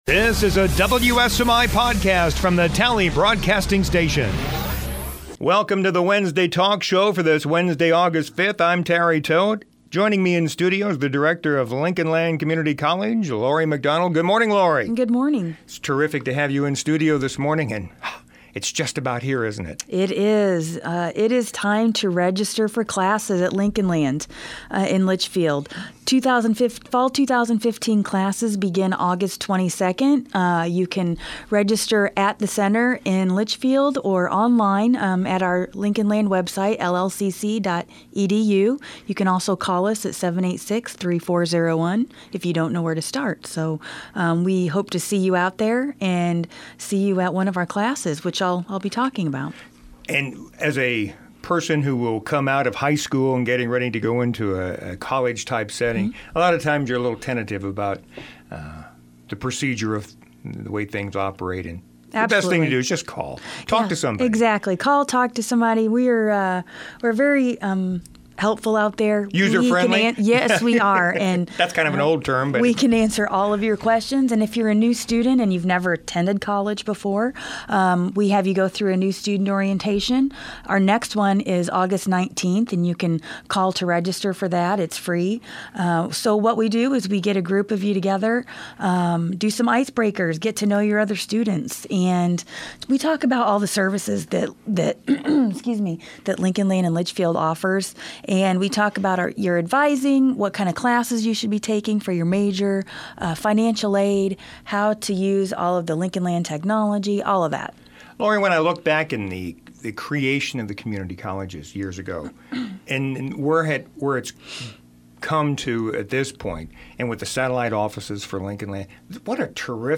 Podcasts - Wednesday Talk